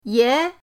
ye2.mp3